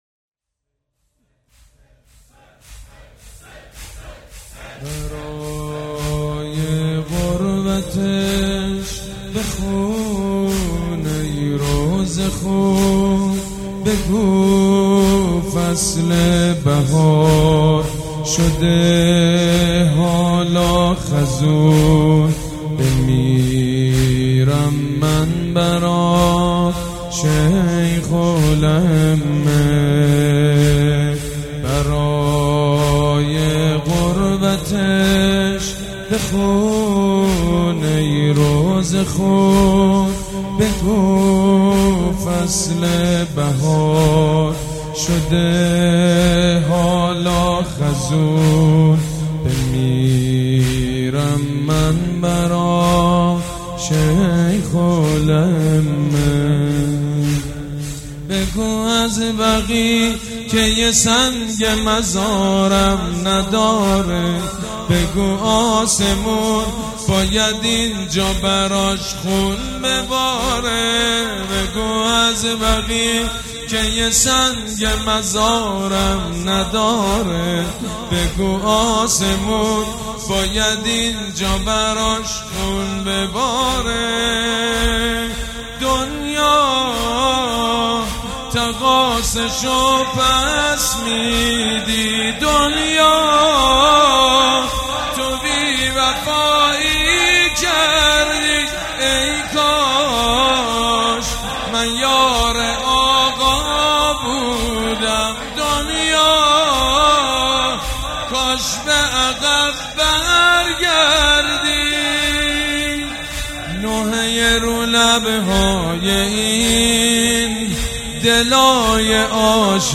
مداح
حاج سید مجید بنی فاطمه
شهادت امام صادق (ع)